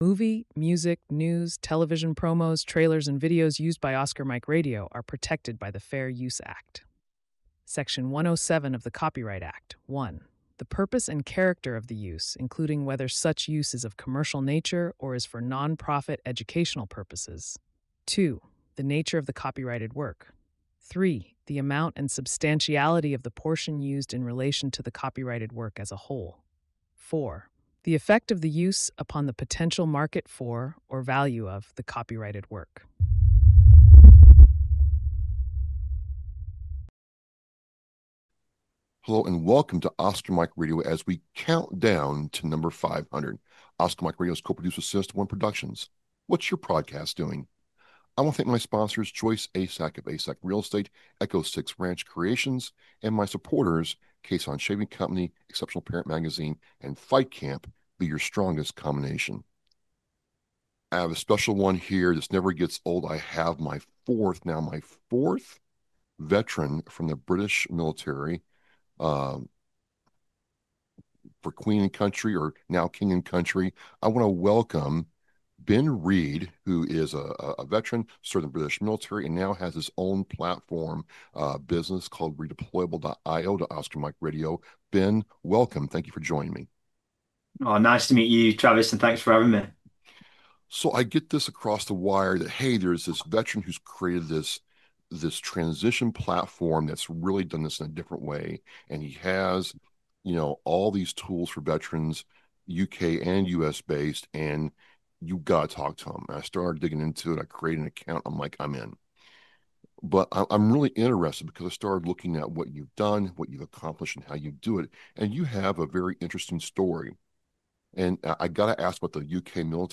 My first interview with an international guest.